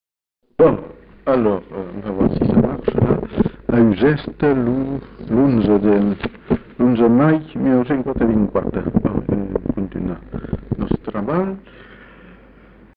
Annonce
Lieu : Uzeste
Genre : parole